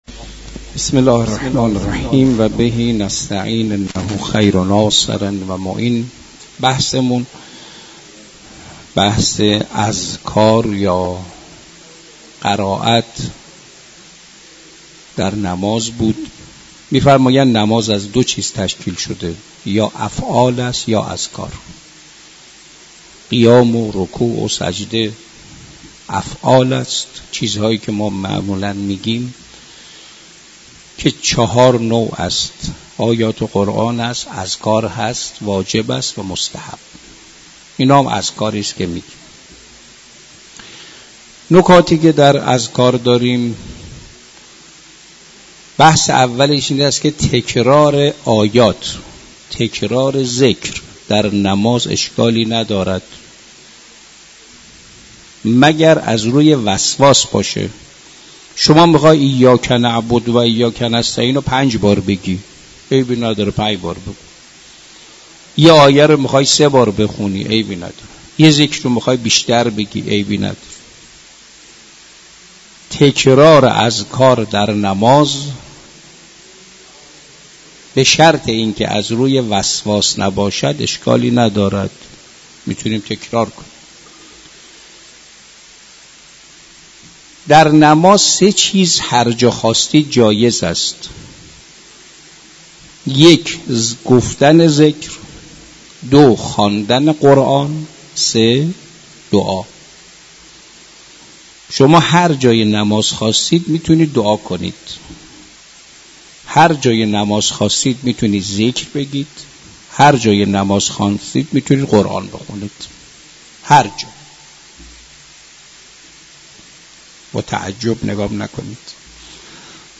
در مسجد دانشگاه